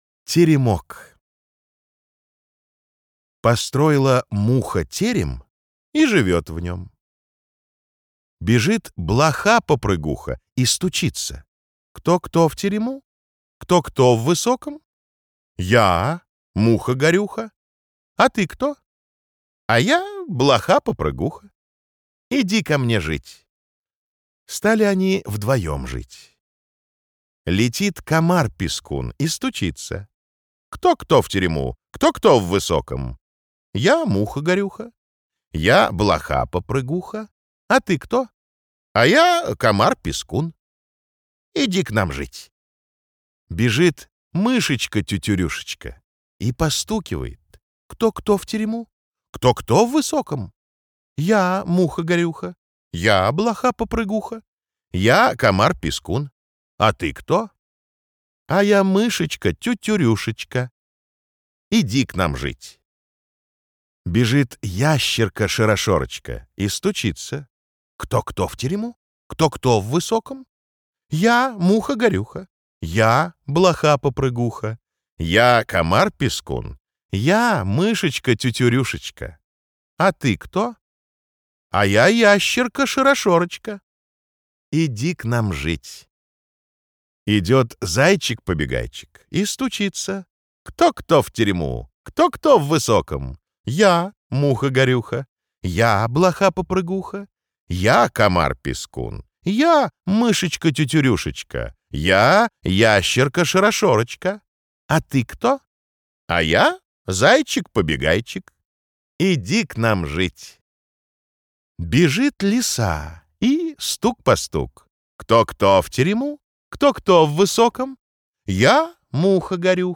Главная Аудиокниги Для детей
Классическую литературу в озвучке «Рексквер» легко слушать и понимать благодаря профессиональной актерской игре и качественному звуку.
Афанасьев_Теремок_Русская народная сказка.mp3